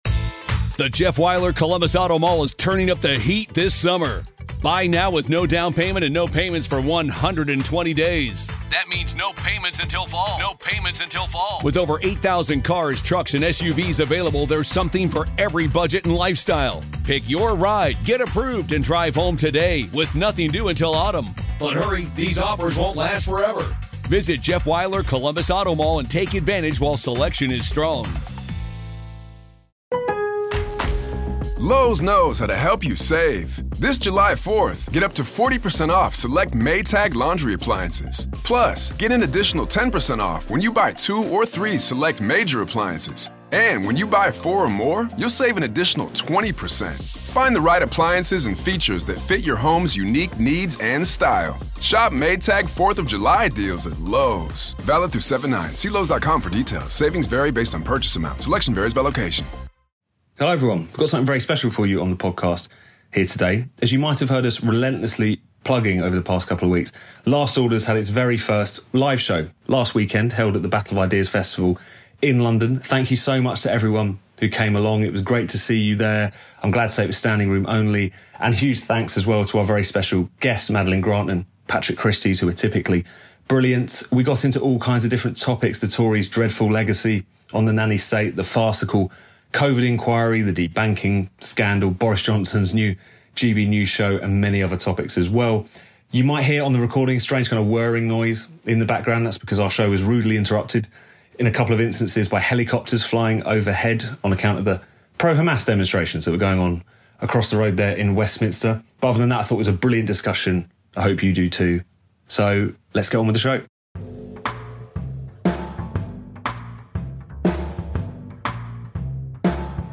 Live at the Battle of Ideas!